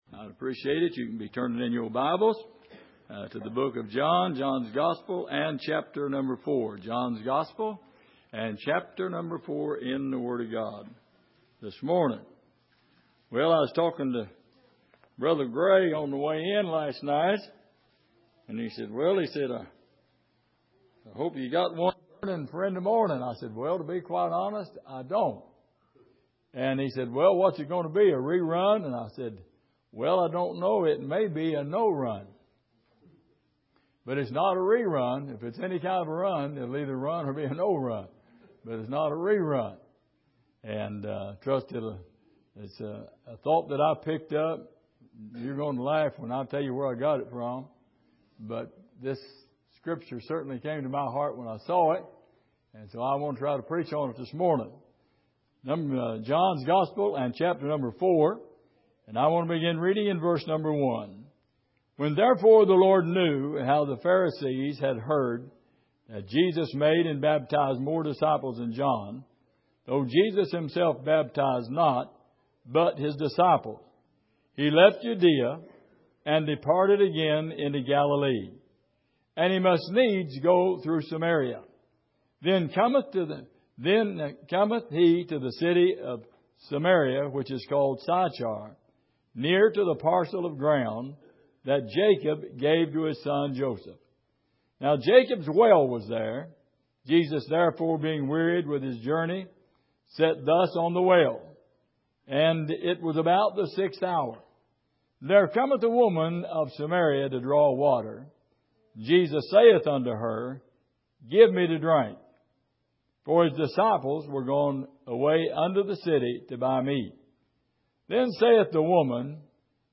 John 4:1-10 Service: Sunday Morning Have You Got A Well?